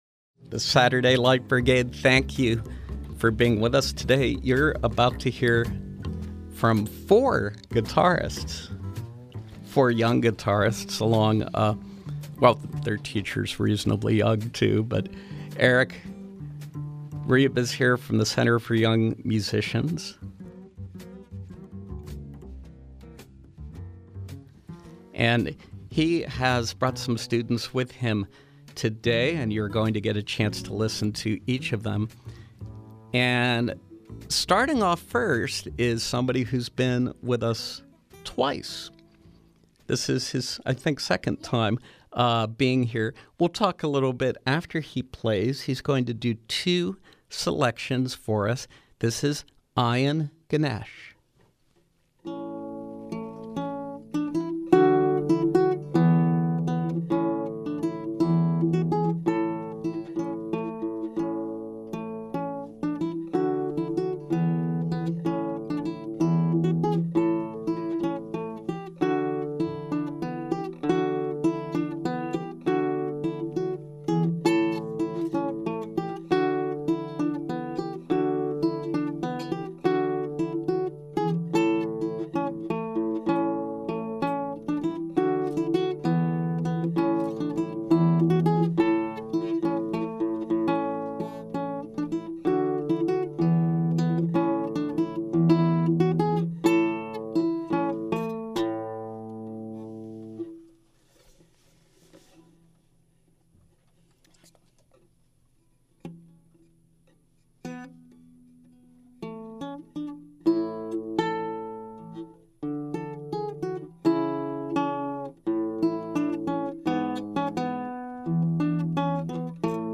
From 10/20/2018: Four guitarists (ages 8 through 17) from the Center for Young Musicians
Guitarists from the Center for Young Musicians on SLB